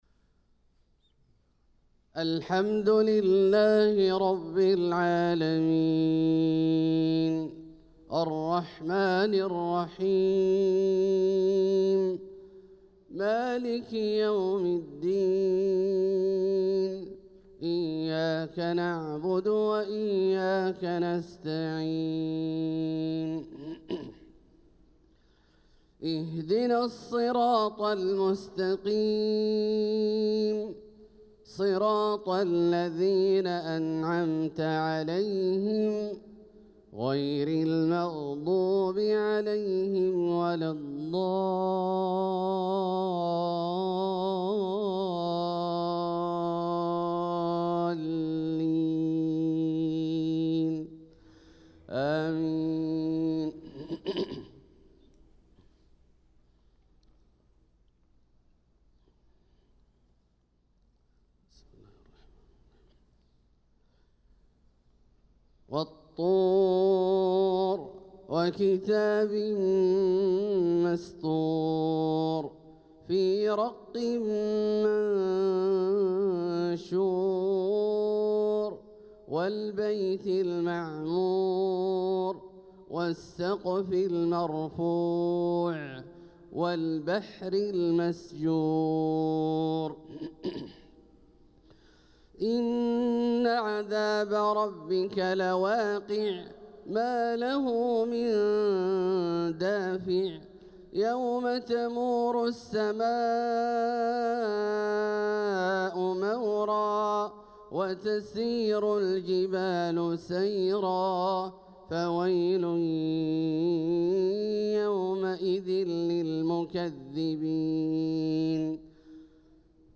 صلاة الفجر للقارئ عبدالله الجهني 23 صفر 1446 هـ